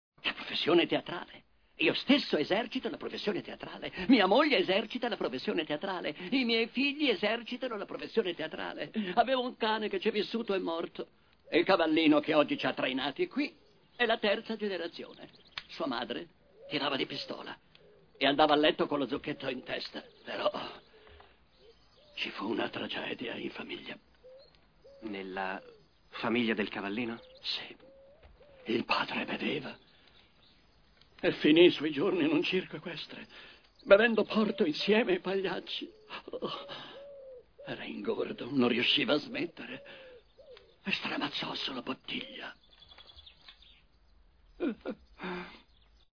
Elio Pandolfi nel film "Nicholas Nickleby", in cui doppia Nathan Lane.